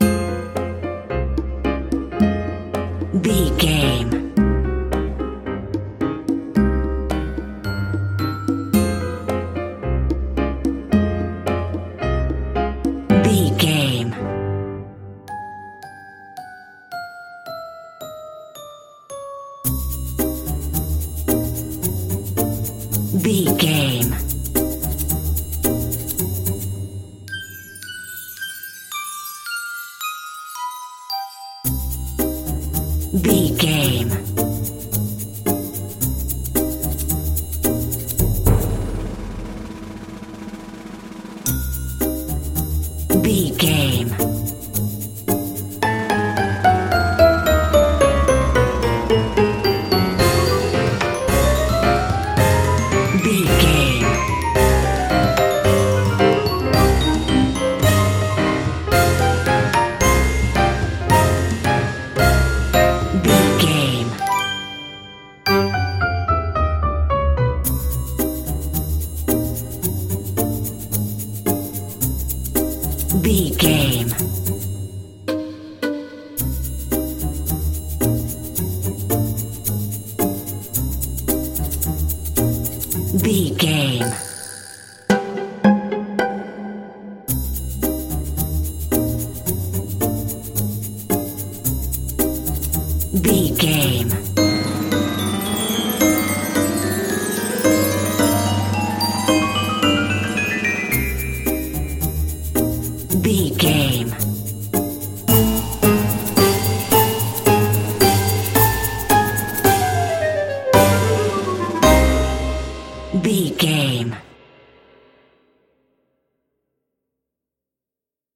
Aeolian/Minor
percussion
strings
silly
circus
goofy
comical
cheerful
perky
Light hearted
quirky